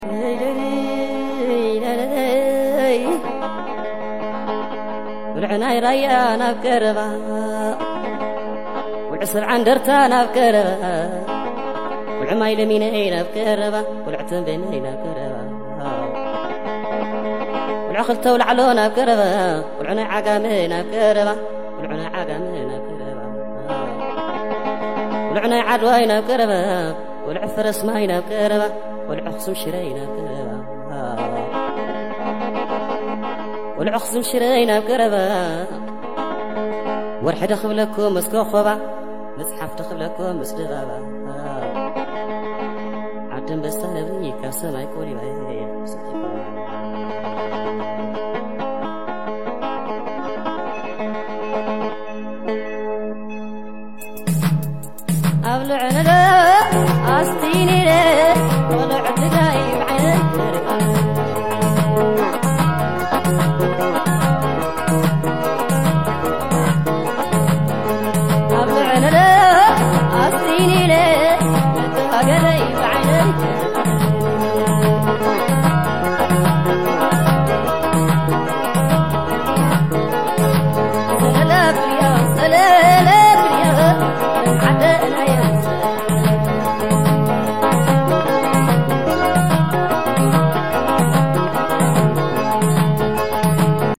old tigrigna music